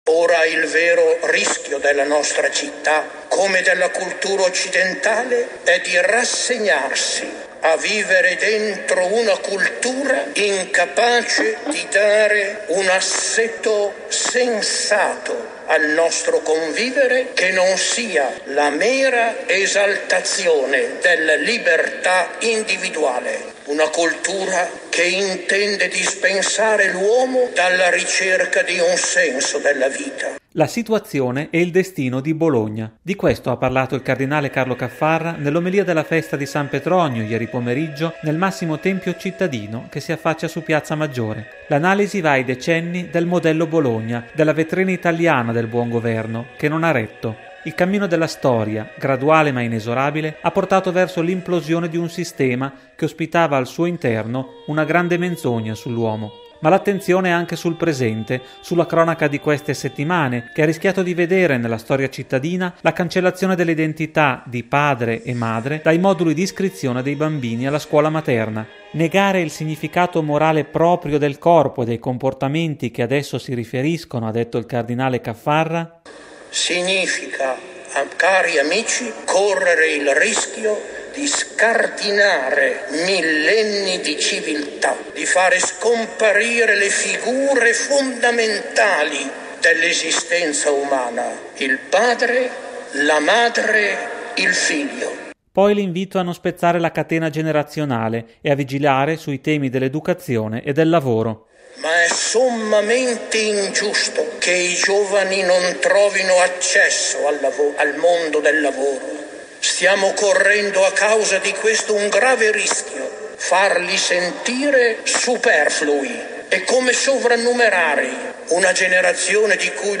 La situazione e il destino di Bologna: di questo ha parlato il cardinale Carlo Caffarra nell’omelia della festa di San Petronio nel massimo tempio cittadino che si affaccia su Piazza Maggiore.
In un giorno di festa di fronte a migliaia di fedeli il cardinale Carlo Caffarra ha voluto ricordare poi la strage di immigrati di qualche giorno fa nel mare di Lampedusa: